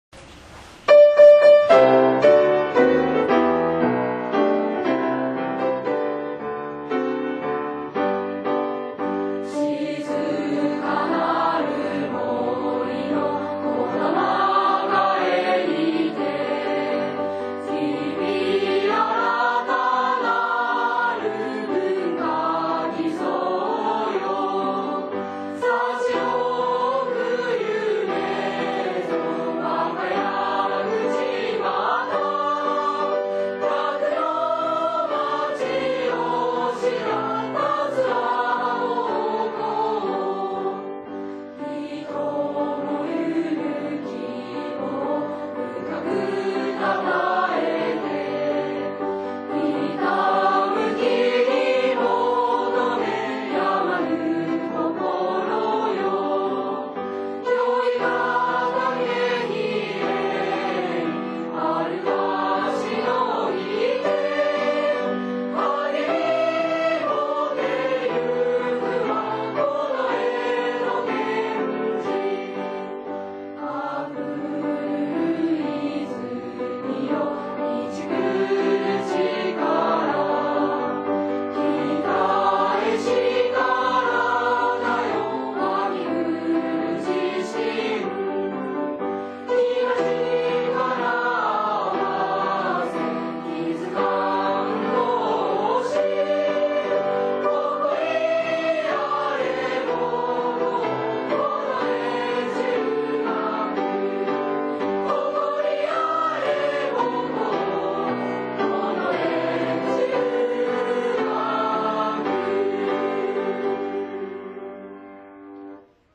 以下に，君たちの先輩たちが歌ってくれた１番から３番までの歌詞を歌っている合唱が聞けるリンクを貼り付けておきます。